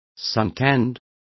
Complete with pronunciation of the translation of suntanned.